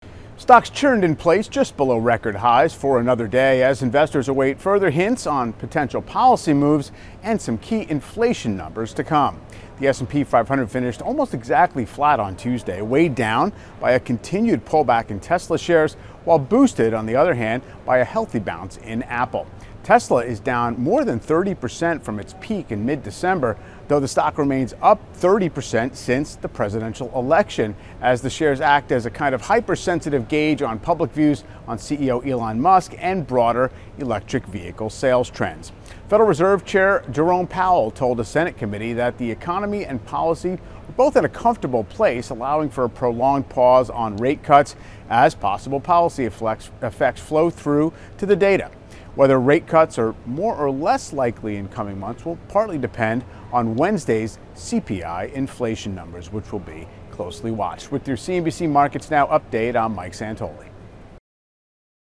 CNBC Markets Now provides a look at the day's market moves with commentary and analysis from Michael Santoli, CNBC Senior Markets Commentator.